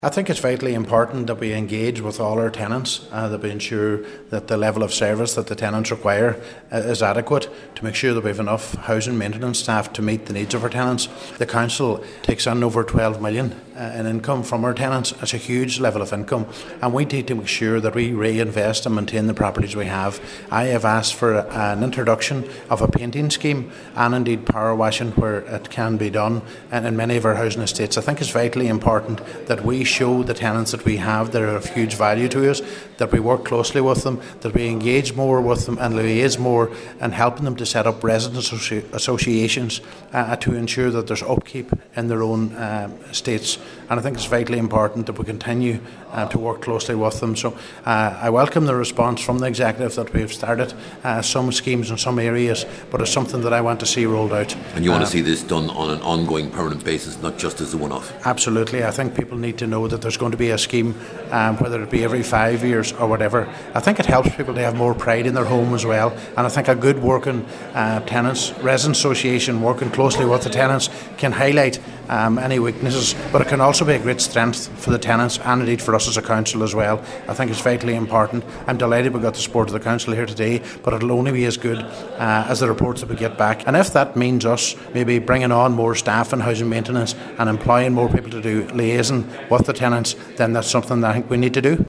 Cllr Brogan is welcoming that, but says more staff and resources should be sought to ensure that everything necessary is identified and addressed: